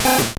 Fichier:Cri 0019 OA.ogg — Poképédia
Cri de Rattata dans Pokémon Or et Argent.